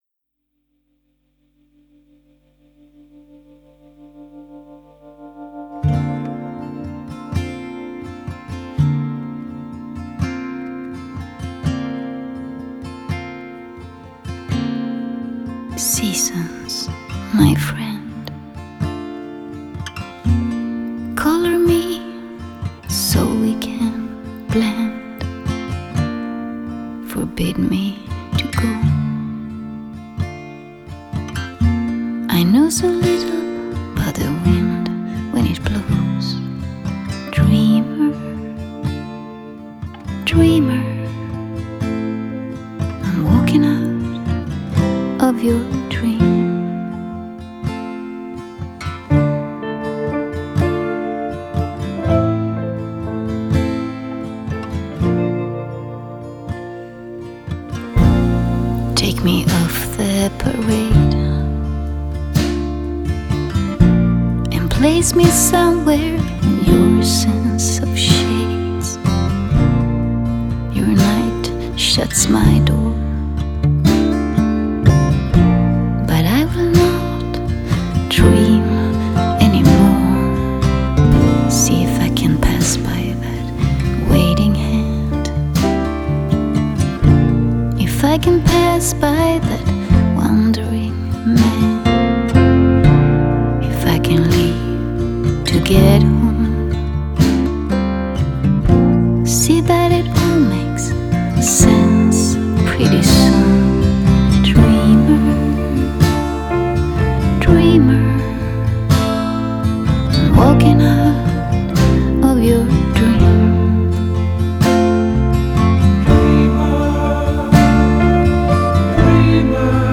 장르: Rock
스타일: Acoustic